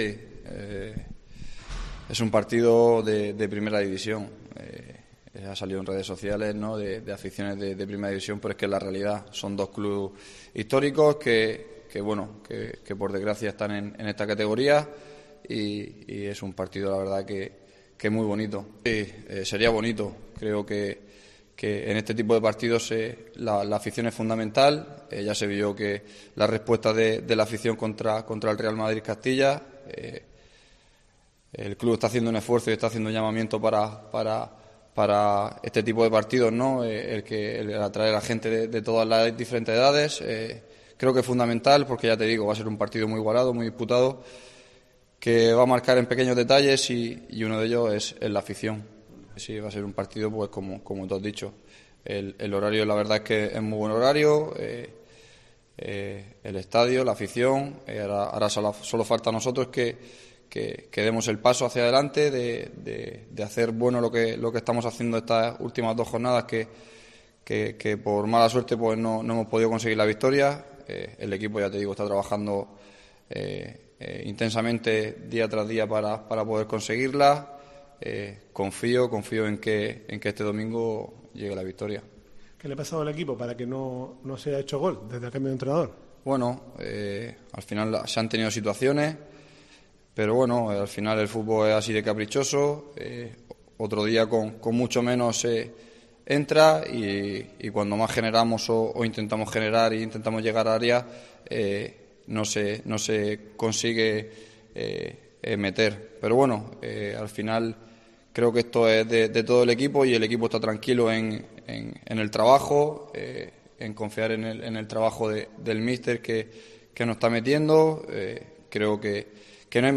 rueda de prensa previa al encuentro de la jornada 14